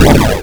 orni_attack/data/sounds/effects/explosion.wav at decde1b7d57997ed82436dda5024fff6ea82b05e
explosion.wav